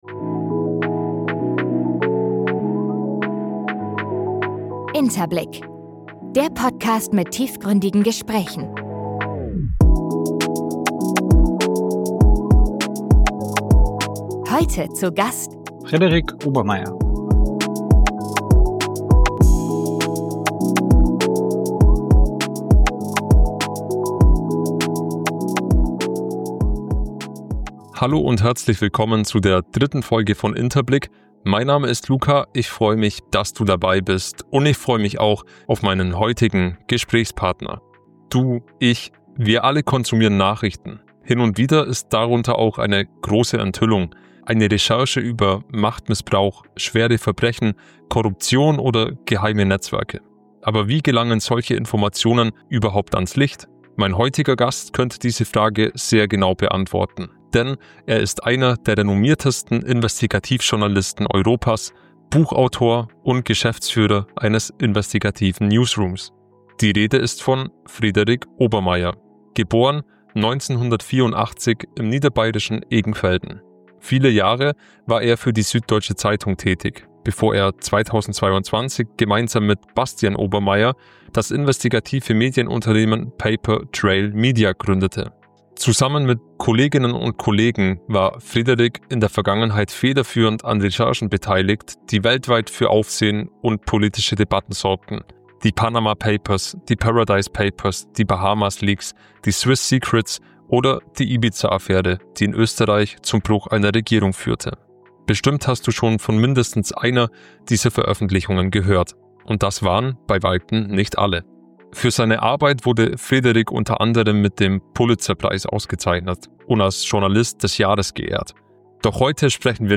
Frederik Obermaier: Der Investigativjournalist im Gespräch - InterBlick | Folge #003 ~ InterBlick Podcast